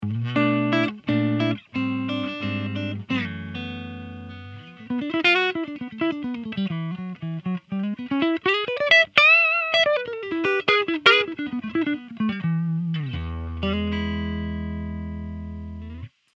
Country riff 2